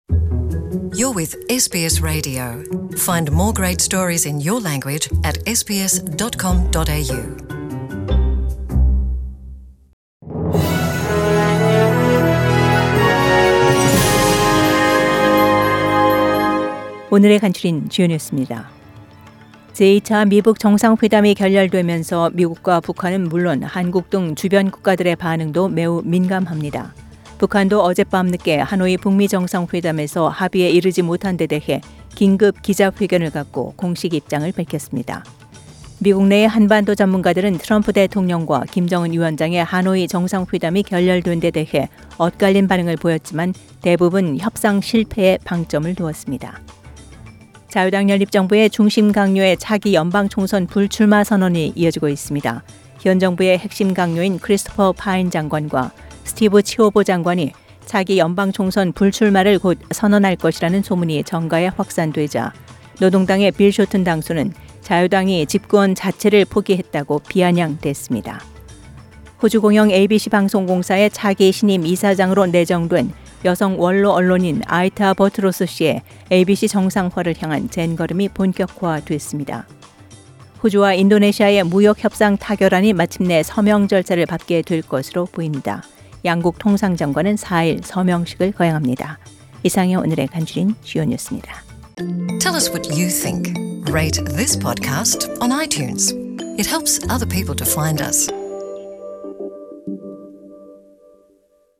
SBS Radio Korean News Bulletin Source: SBS Korean program